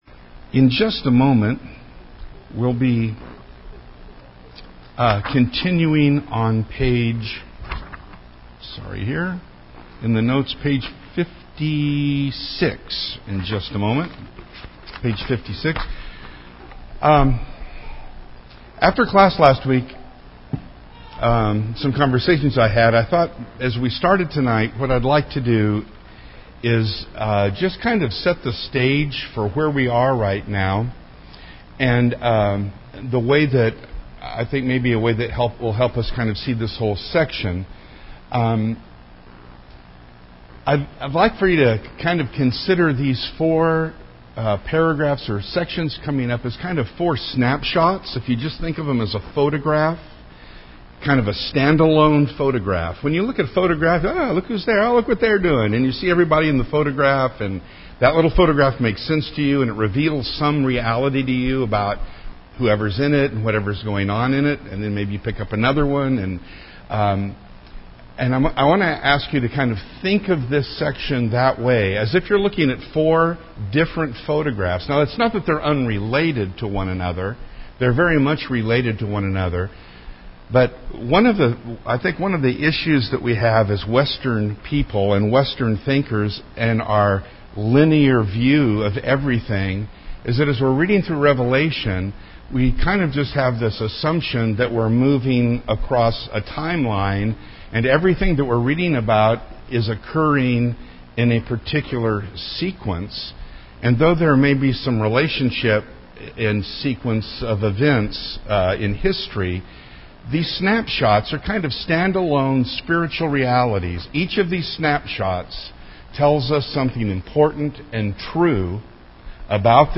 This is the thirtieth part of our Wednesday night class on Revelation.